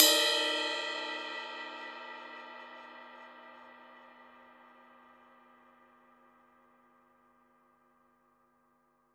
Index of /90_sSampleCDs/Total_Drum&Bass/Drums/Cymbals
ride_cym2.wav